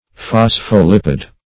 phospholipid.mp3